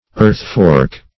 Earthfork \Earth"fork`\, n. A pronged fork for turning up the earth.